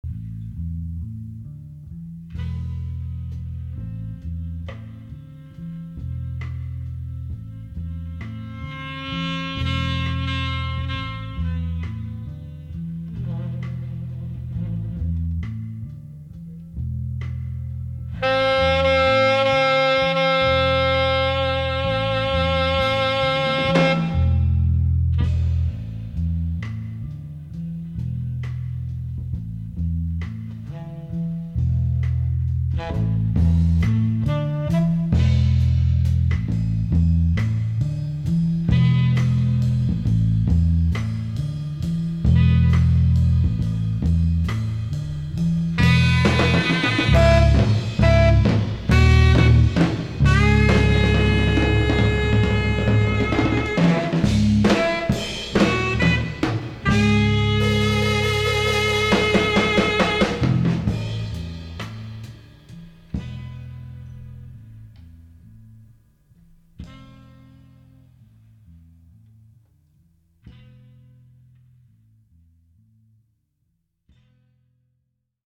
Tenor and Soprano Saxophones
Electric Bass
Drums and Congas